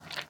1.21.5 / assets / minecraft / sounds / mob / panda / eat9.ogg
eat9.ogg